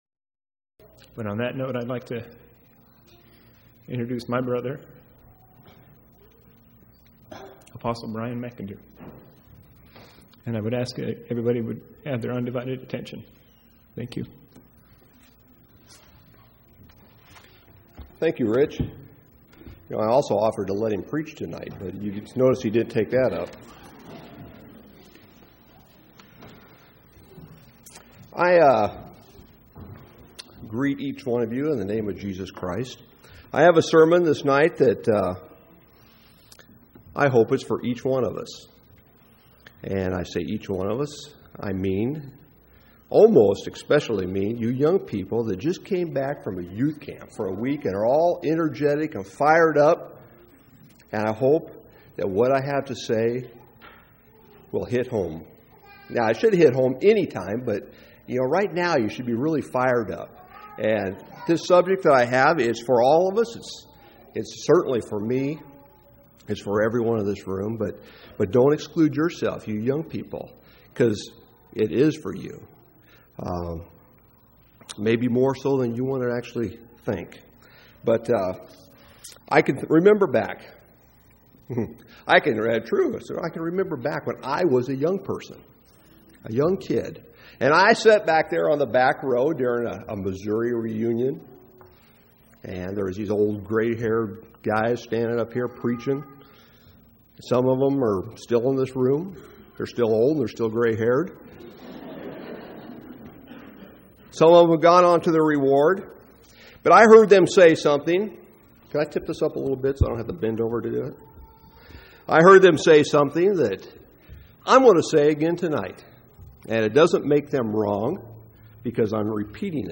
7/29/2005 Location: Missouri Reunion Event